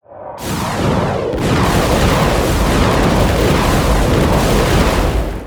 ZombieSkill_SFX
sfx_skill 16_2.wav